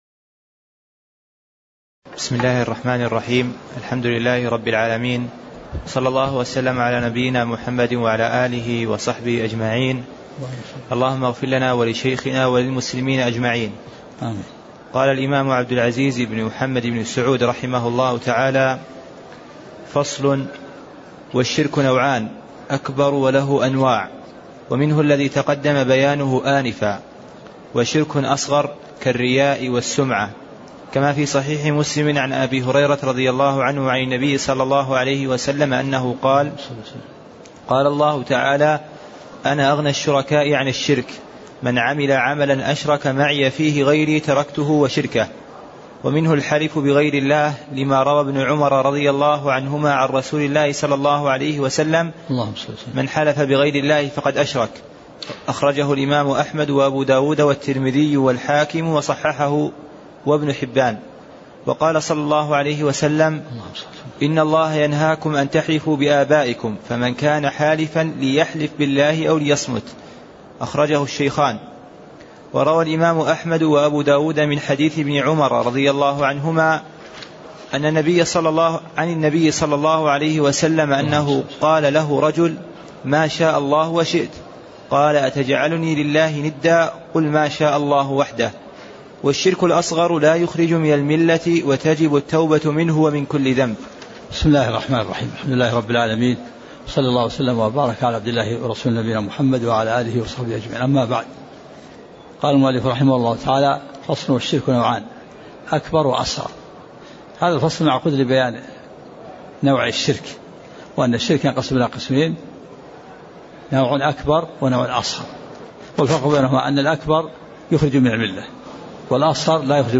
تاريخ النشر ٨ شعبان ١٤٣٤ هـ المكان: المسجد النبوي الشيخ